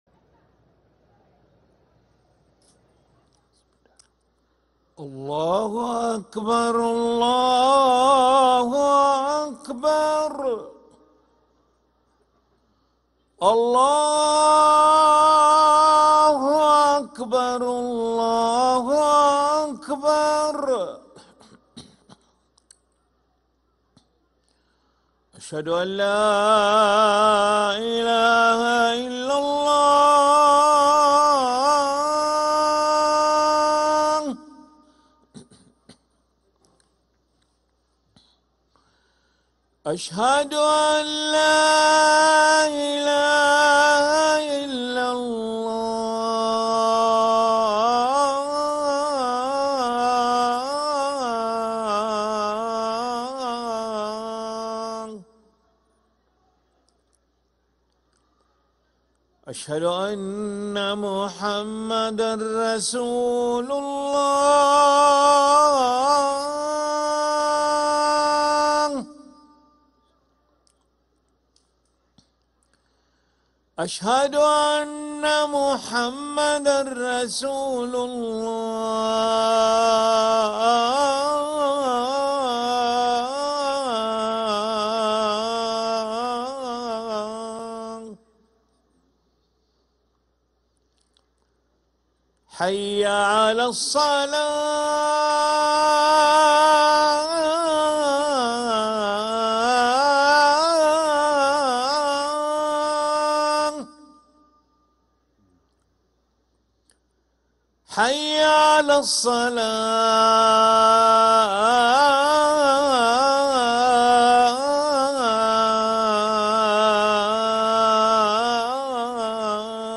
أذان العشاء للمؤذن علي ملا الأحد 14 جمادى الآخرة 1446هـ > ١٤٤٦ 🕋 > ركن الأذان 🕋 > المزيد - تلاوات الحرمين